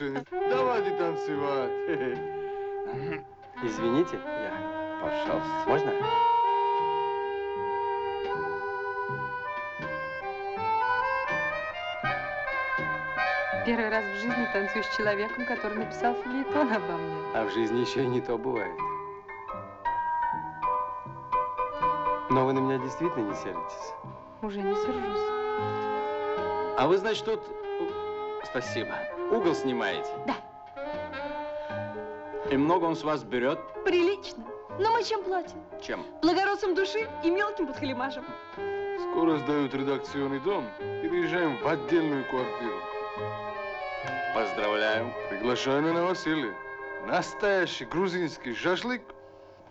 Фрагмент фильма с мелодией: